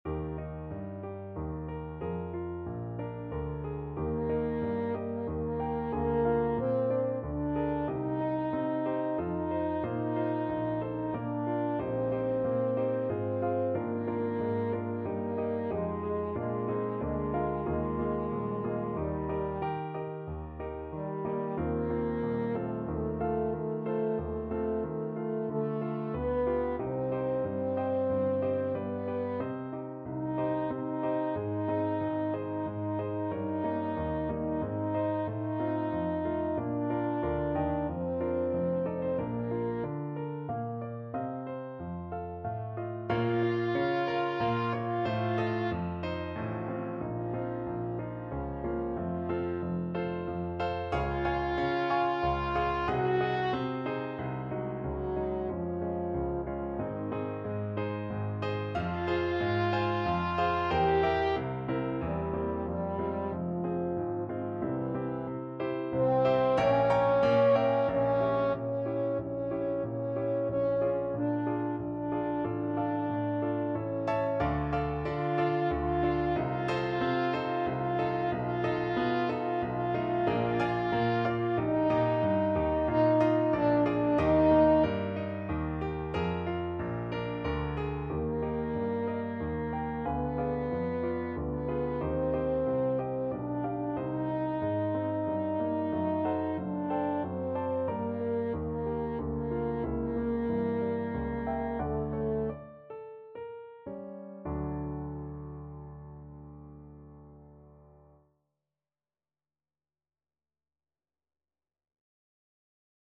Hostias French Horn version
French Horn
Eb major (Sounding Pitch) Bb major (French Horn in F) (View more Eb major Music for French Horn )
3/4 (View more 3/4 Music)
~ = 92 Larghetto
Classical (View more Classical French Horn Music)